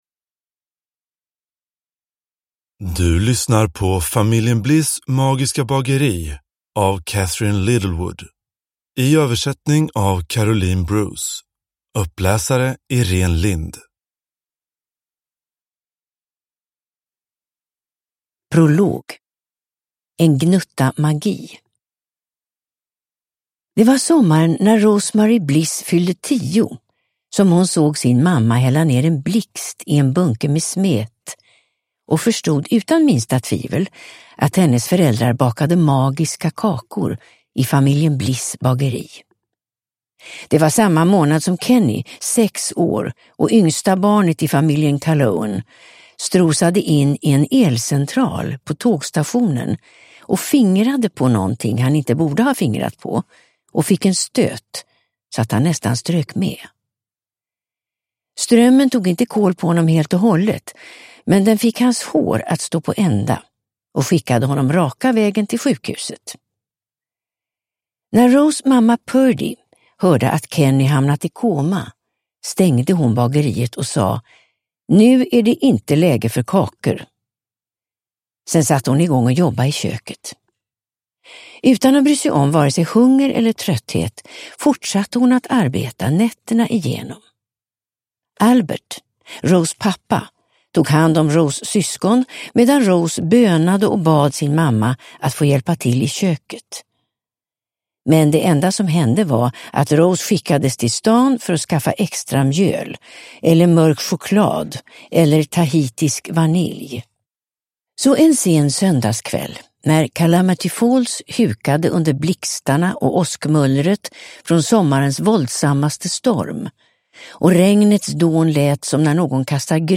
Familjen Bliss magiska bageri – Ljudbok – Laddas ner